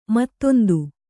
♪ mattondu